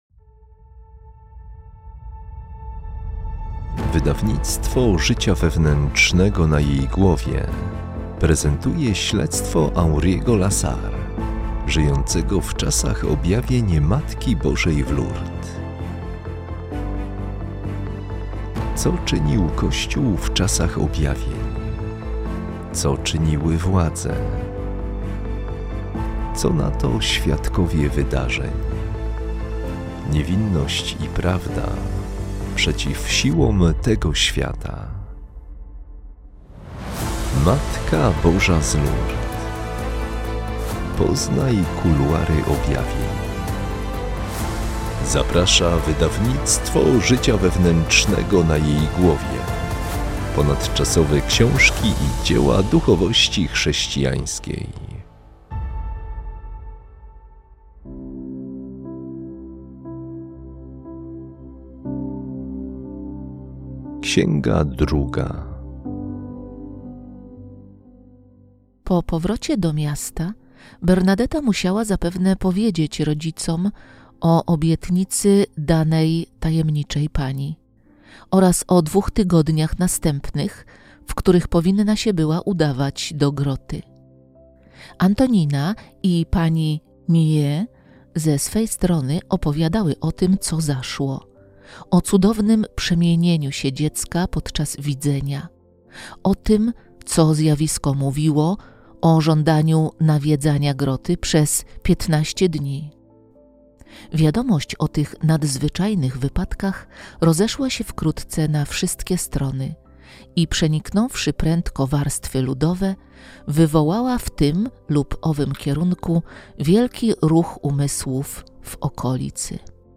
Matka Boża z Lourdes - Henri Lasserre - audiobook